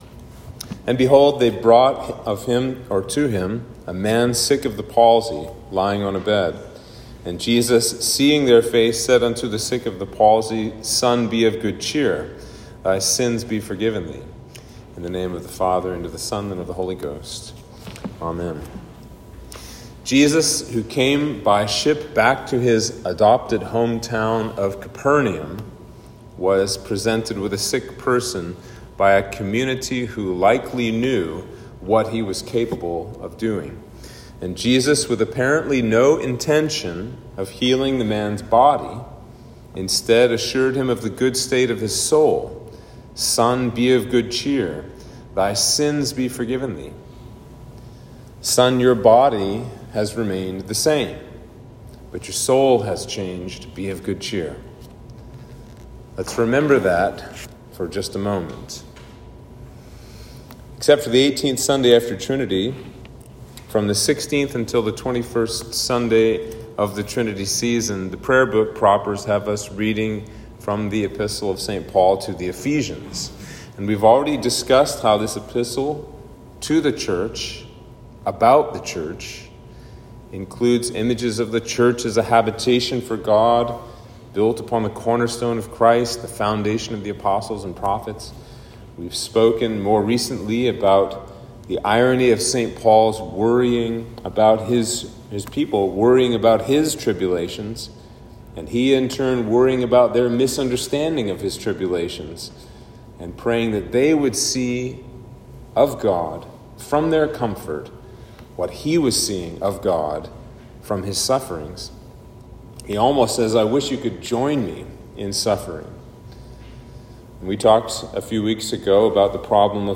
Sermon for Trinity 19